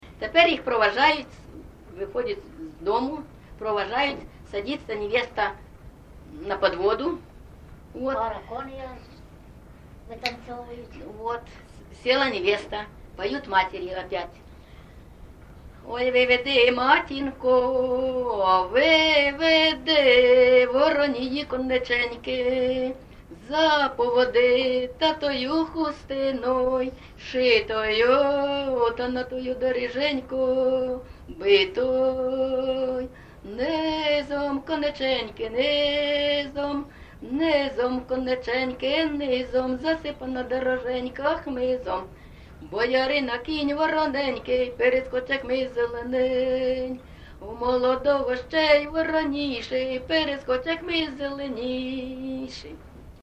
ЖанрВесільні
Місце записум. Маріуполь, Донецька обл., Україна, Північне Причорноморʼя